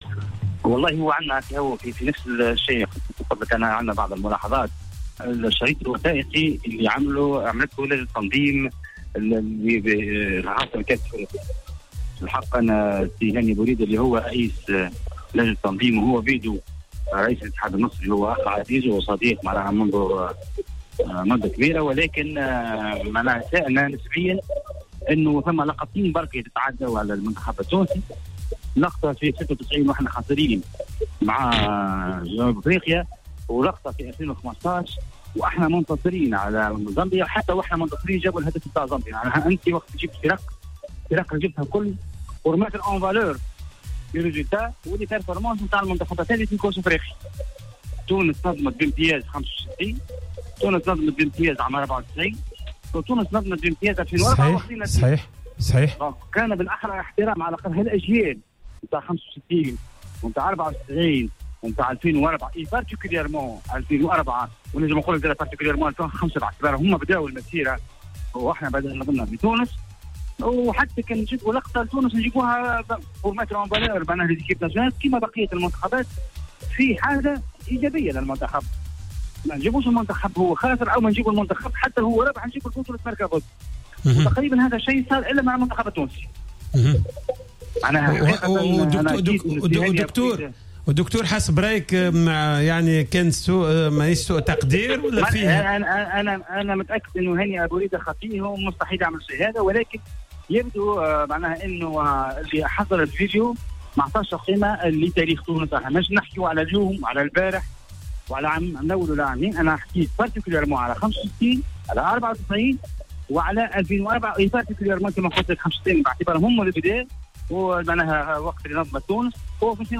أكد رئيس الجامعة التونسية لكرة القدم الدكتور وديع الجريء لدى تدخله في حصة "Planète Sport" أن هناك إستياء من تغييب تاريخ المنتخب الوطني التونسي في الشريط الوثائقي الذي تم عرضه خلال حفل قرعة كأس إفريقيا 2019.